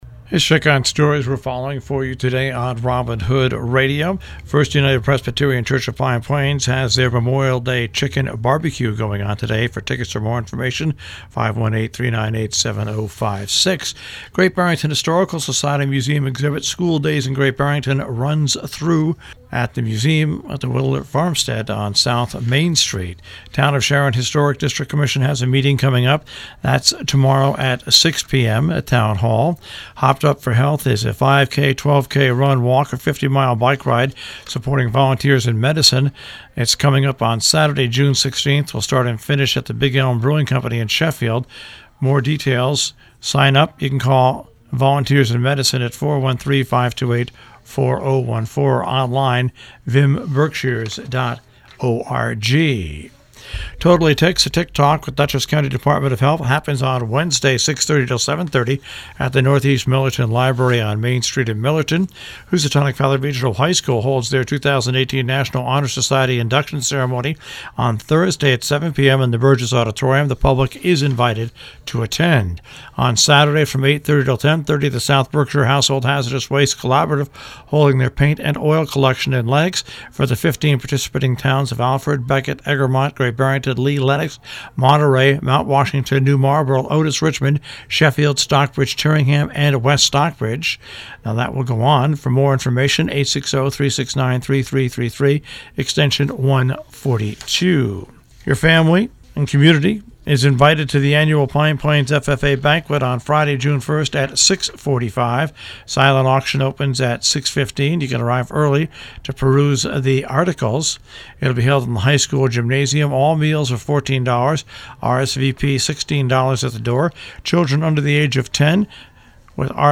covers news and events in the Tri-State Region on The Breakfast Club